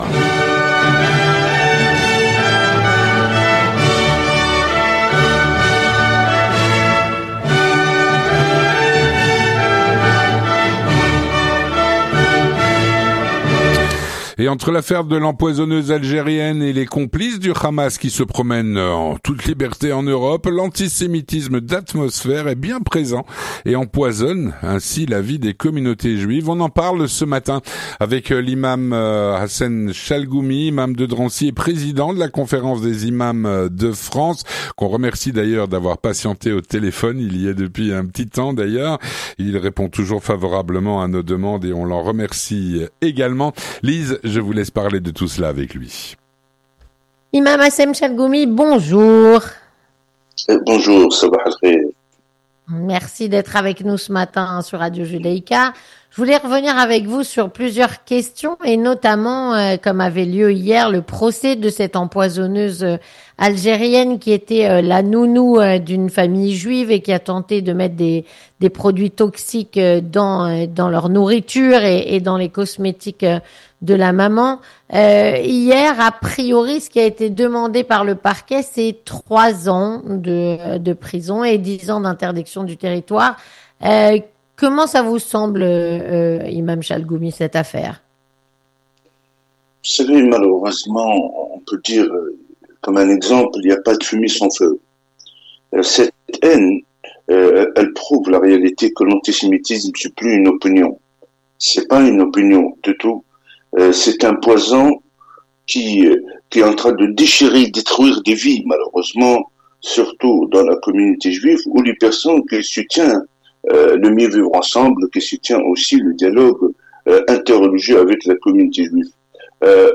On en parle avec l’Imam Chalghoumi, Imam de Drancy et président de la conférence des imams de France.